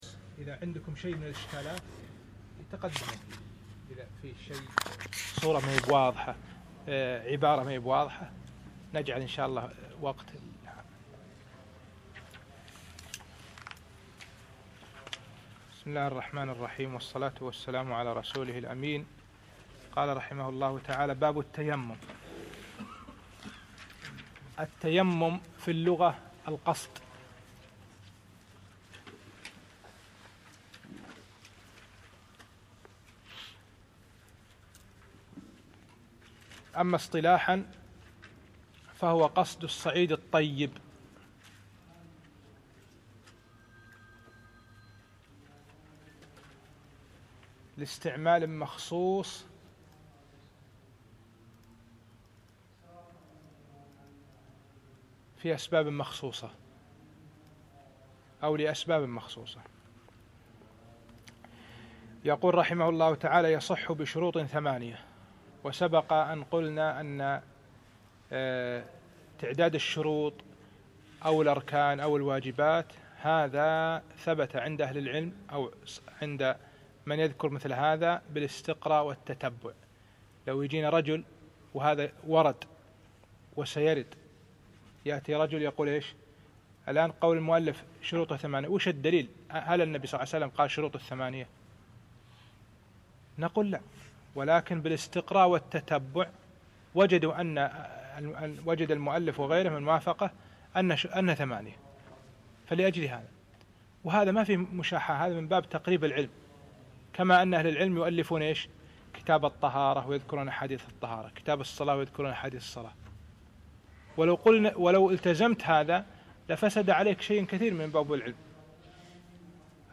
الدرس الثالث من بداية باب التيمم إلى نهاية كتاب الطهارة